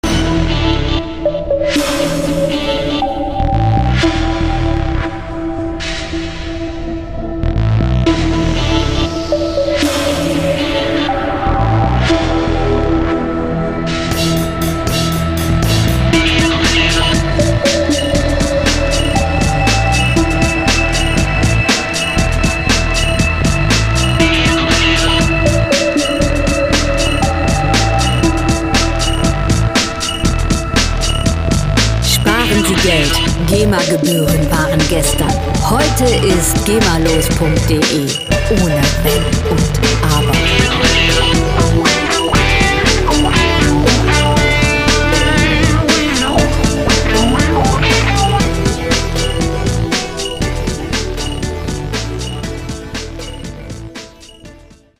Rockmusik - Harte Männer
Musikstil: Progressive Rock
Tempo: 119 bpm
Tonart: E-Moll
Charakter: kraftvoll, robust
Instrumentierung: E-Gitarre, E-Bass, Drums, Synthie